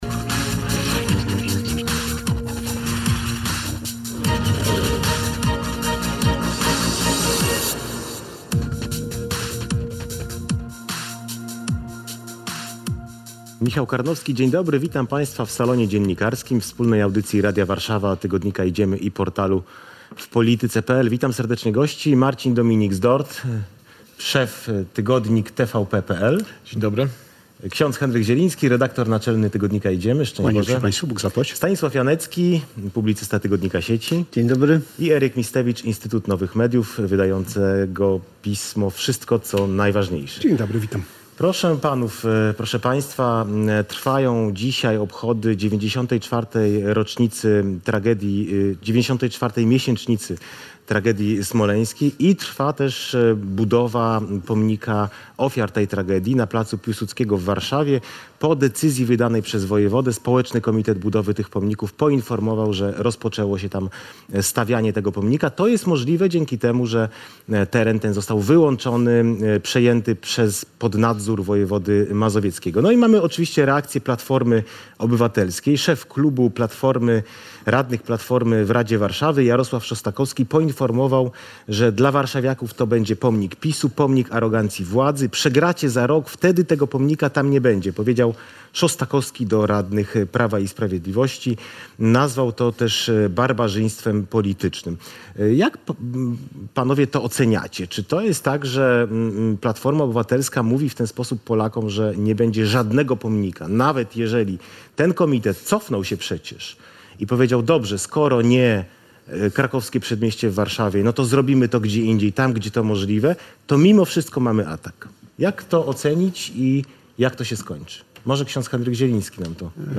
W sobotni poranek rozmowa w studiu „Salonu Dziennikarskiego” toczyła się między innymi wokół tematów: nowelizacji ustawy o IPN i porozumieniu lekarzy rezydentów z Ministrem Zdrowia.